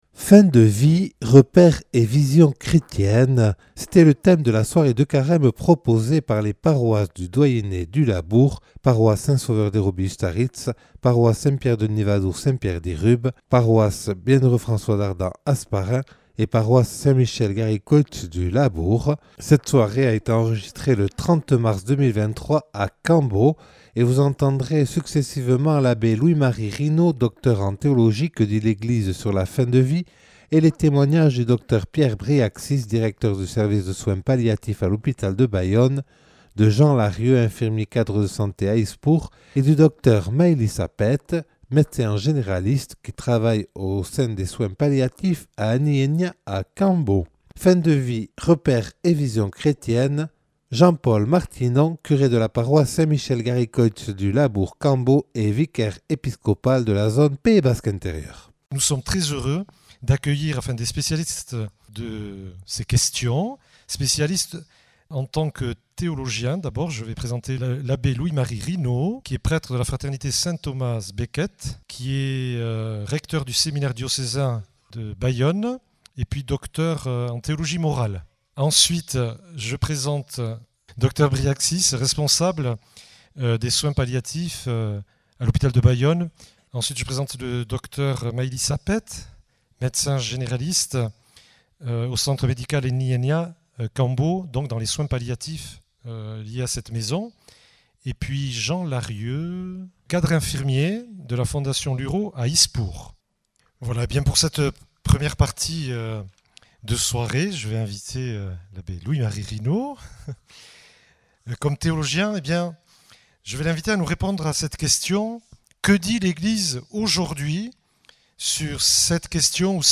(Enregistré le 30 mars 2023 à Cambo lors d’une soirée de Carême proposée par les paroisses du doyenné du Labourd).